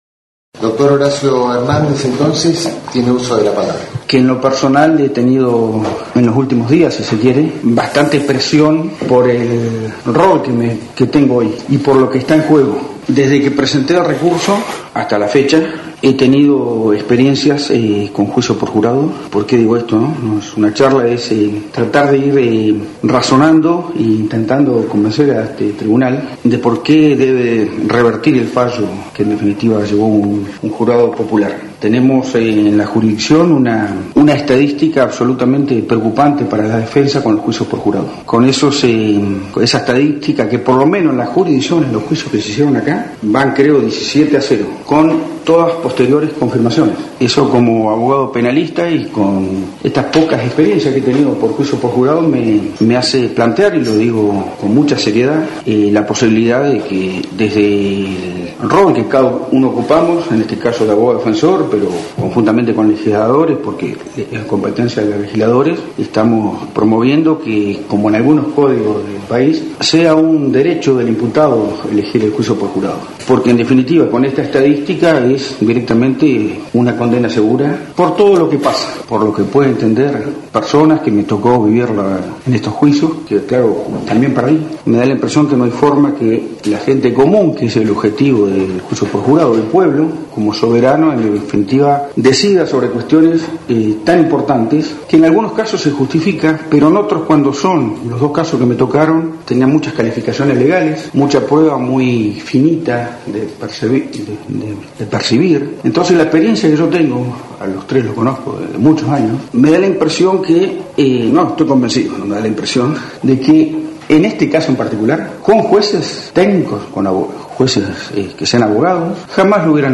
Audiencia de revisión